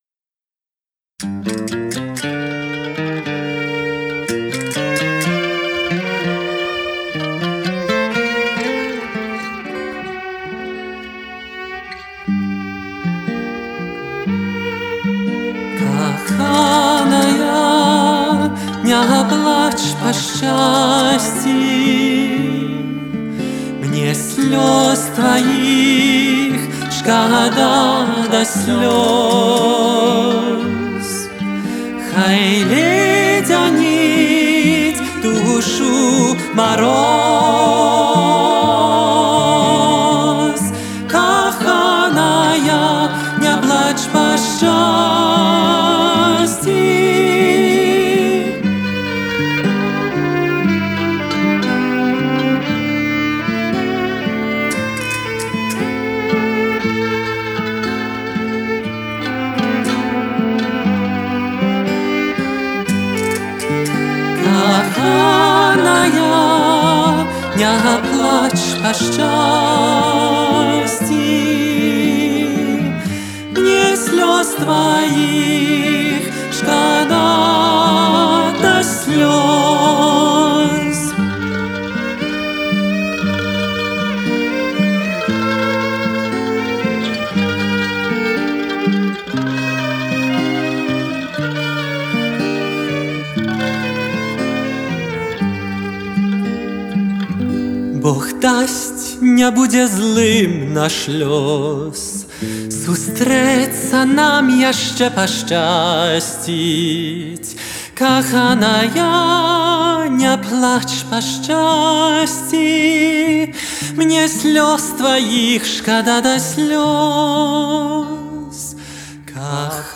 И ещё одна нежная песня.